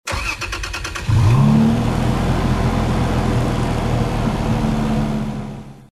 Home gmod sound vehicles tdmcars toyfj
enginestart.mp3